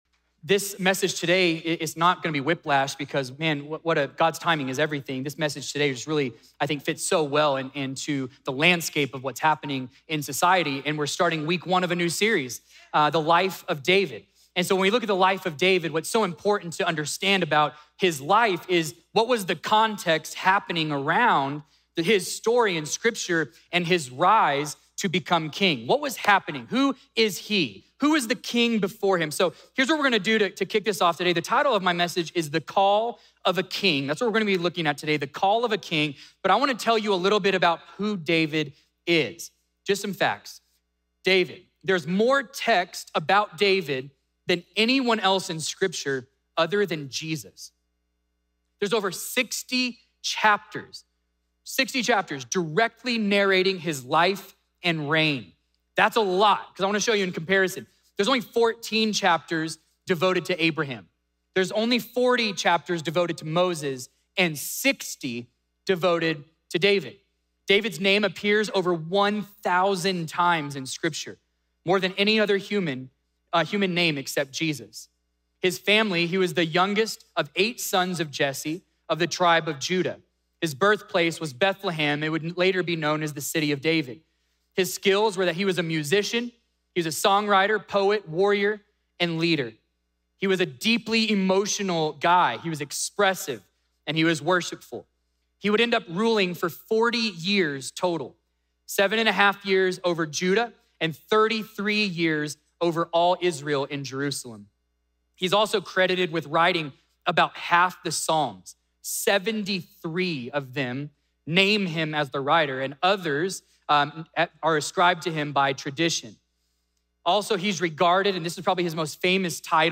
A message from the series "Summer on the Mount."